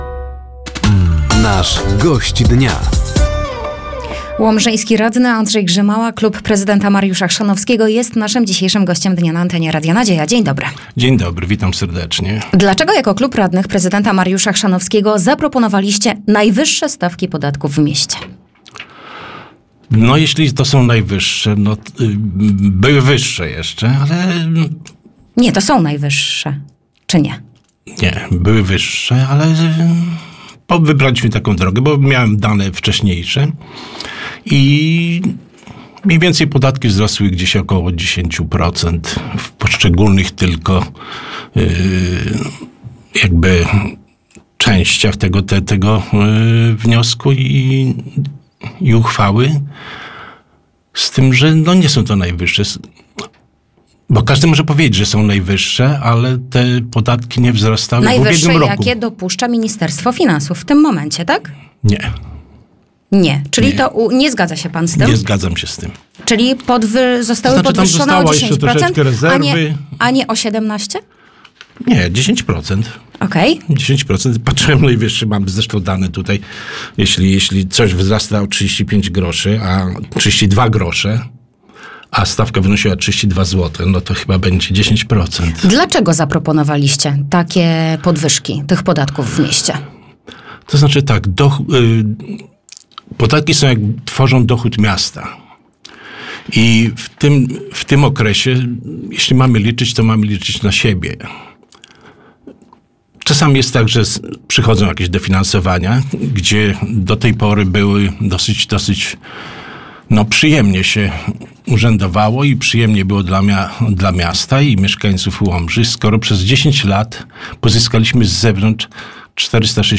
Poniedziałkowym (10.11) Gościem Dnia był Andrzej Grzymała, radny miasta Łomża. Rozmowa dotyczyła sytuacji finansowej miasta i niedawnych podwyżek podatku od nieruchomości.